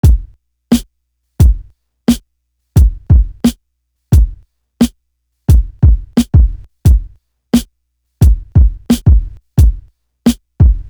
Stuck To You Drum.wav